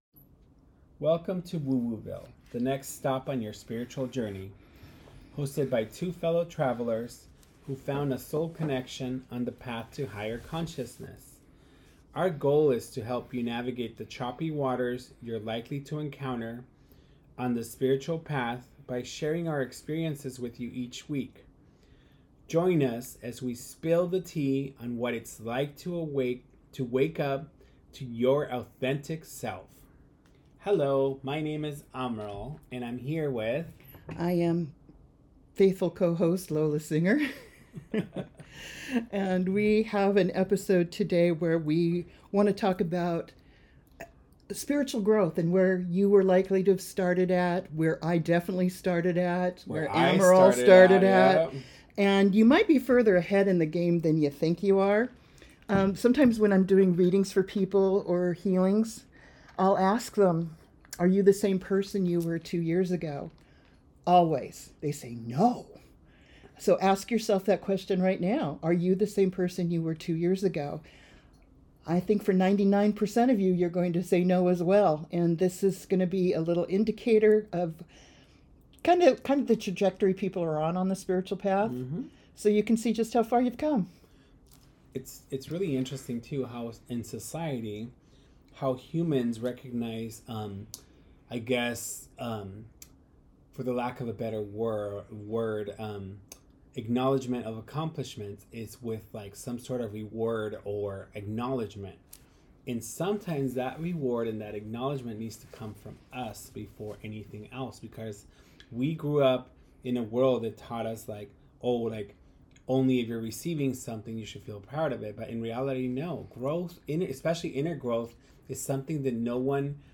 With personal stories, laughter, and honest reflection, they explore how wounds become wisdom, how control turns into trust, and how self-love becomes the foundation for true freedom.